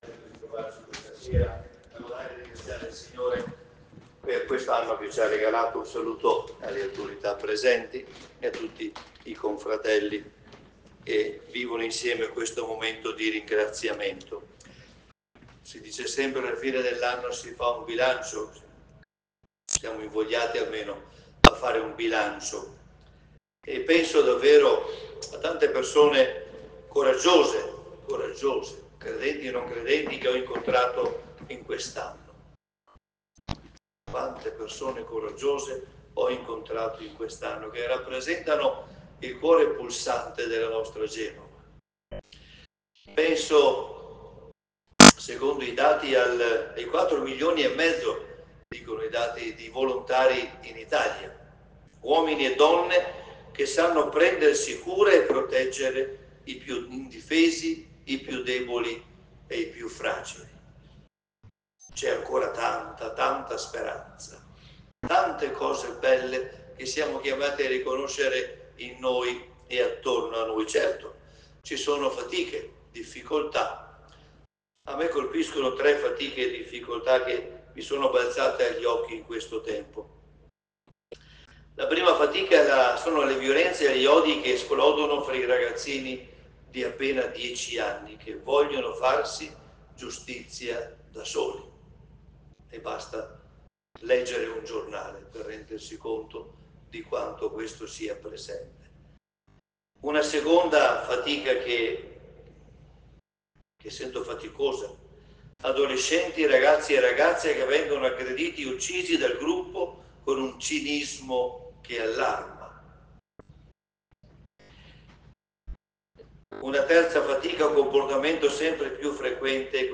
Martedì 31 dicembre 2024 nella Chiesa del Gesù Mons. Marco Tasca ha presieduto i Primi Vespri di Maria Ss. Madre di Dio e ha partecipato al canto del Te Deum per la fine dell’anno civile.